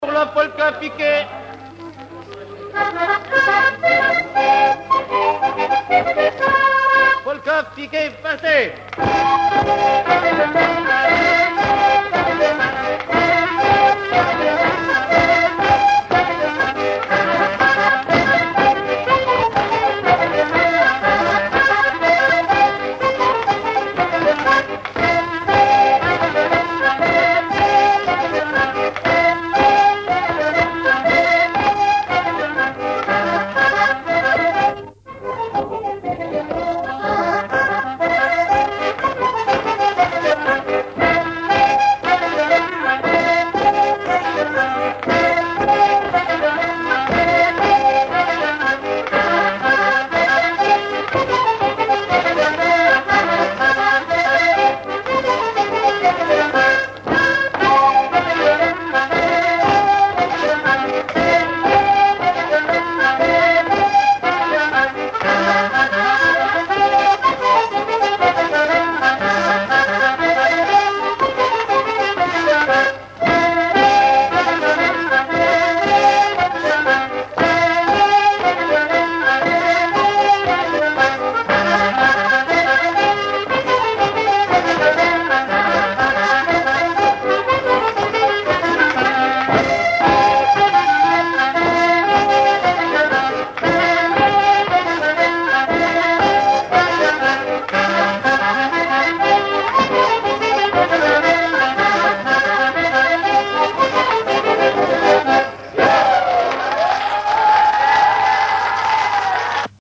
danse : polka piquée
Pièce musicale inédite